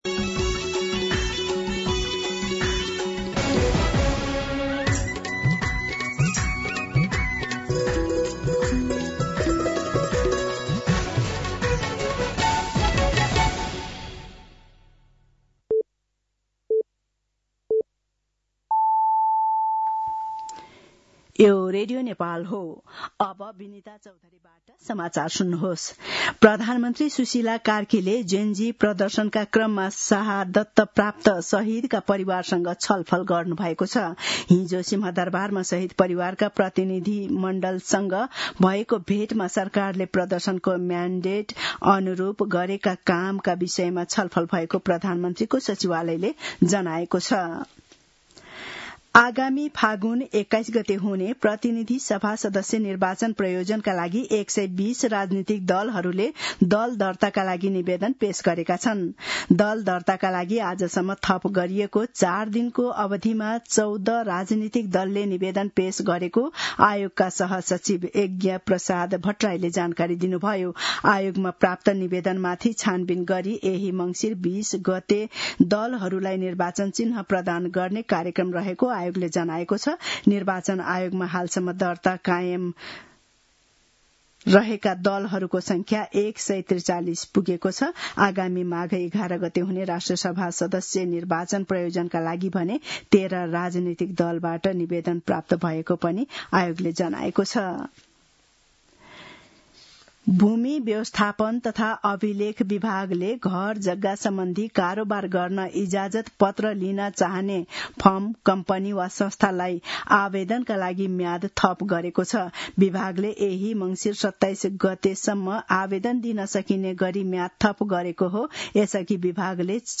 मध्यान्ह १२ बजेको नेपाली समाचार : १५ मंसिर , २०८२